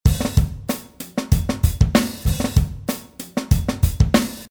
It slices a loop and re-arrange it in a different order, you can also vary the volume and pan of the single slices. I used it to produce the 7/4 beat in the drum part:
virus-drums-postCyclone.mp3